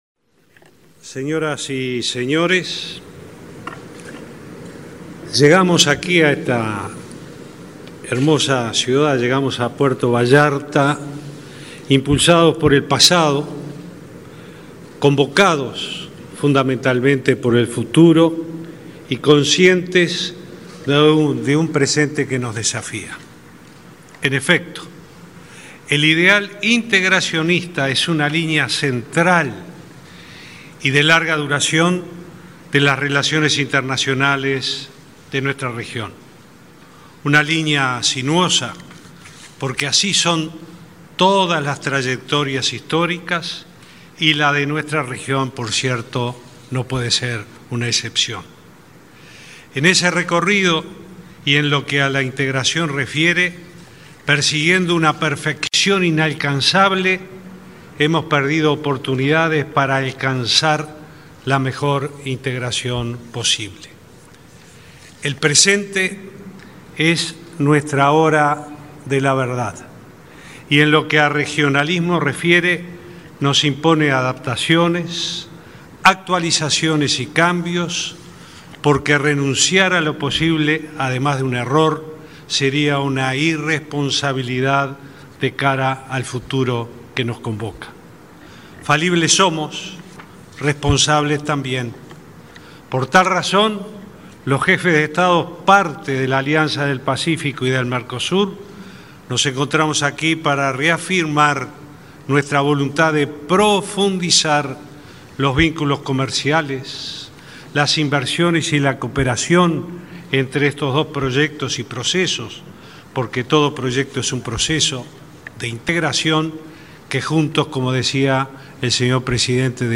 "La integración internacional es condición fundamental de todo proyecto de desarrollo económico y social de nuestros países y de nuestra región”, afirmó el presidente Vázquez en México. “Por eso abogamos por una profundización de la hoja de ruta entre el Mercosur y la Alianza del Pacífico”, señaló en el encuentro de jefes de Estado de ambos bloques.